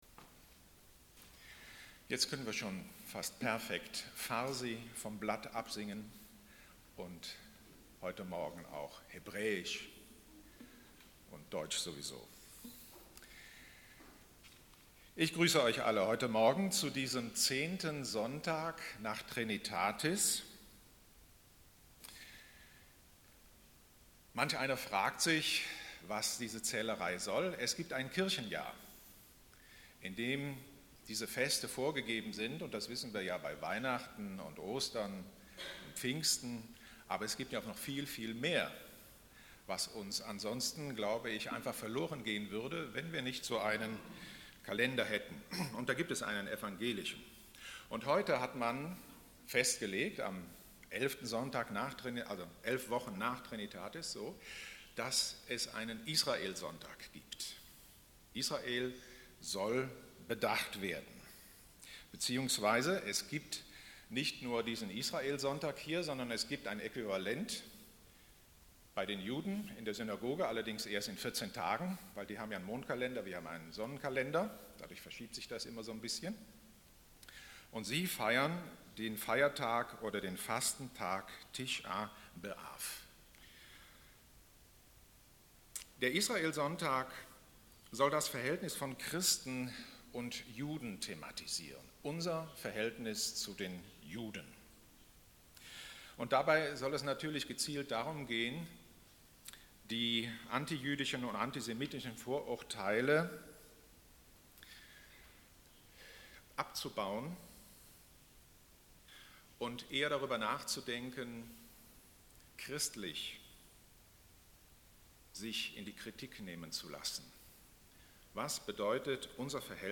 Predigt vom 31.07.2016